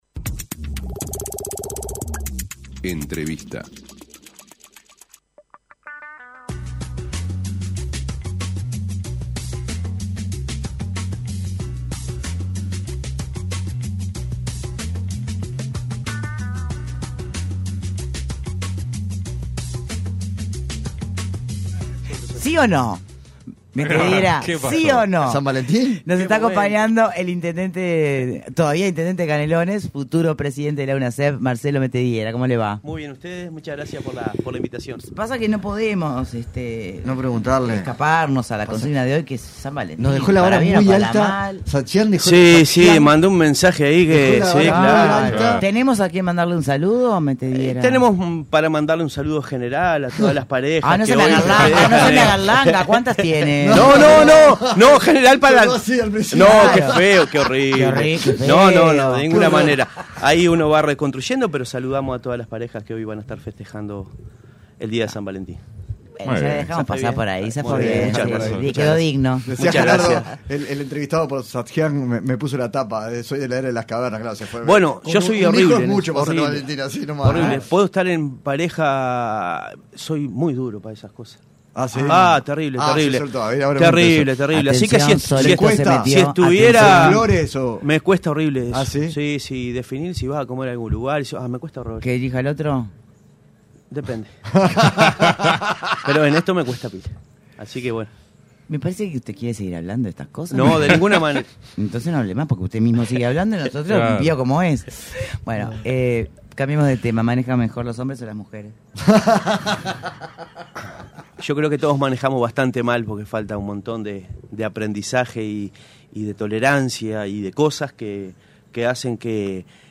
Entrevista a Marcelo Metediera